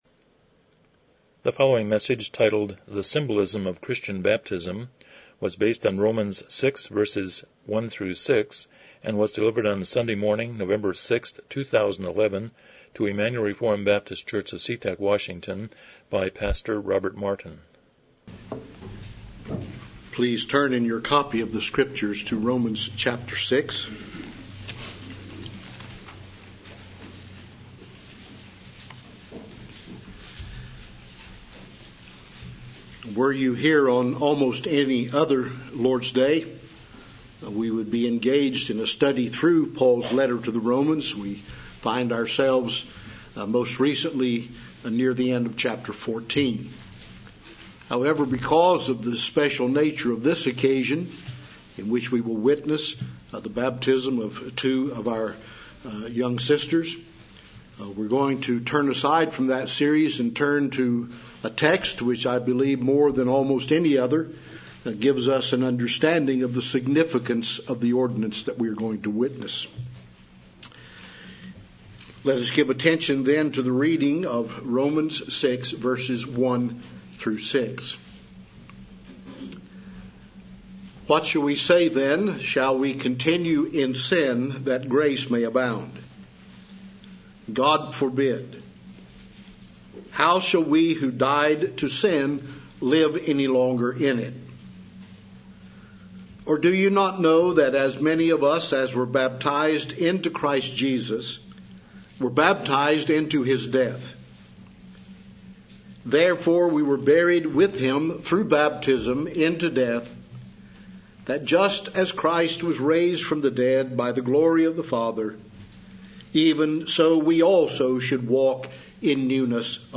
Passage: Romans 6:1-6 Service Type: Morning Worship